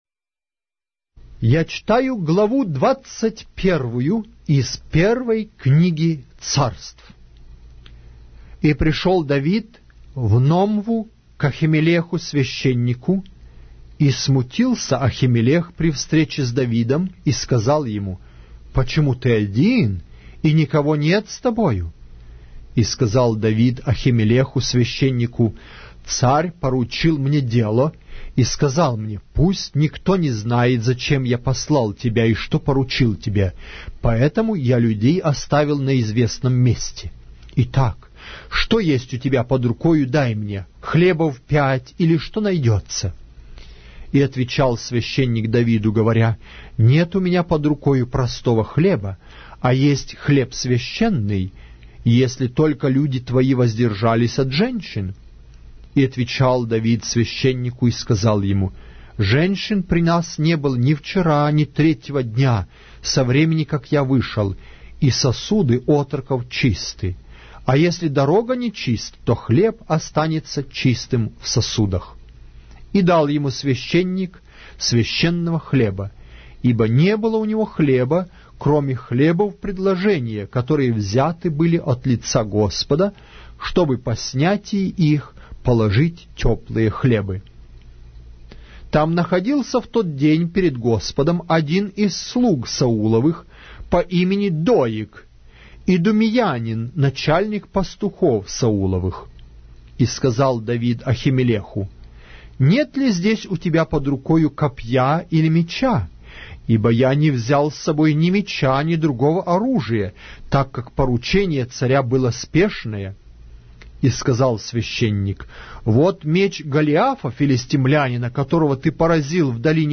Глава русской Библии с аудио повествования - 1 Samuel, chapter 21 of the Holy Bible in Russian language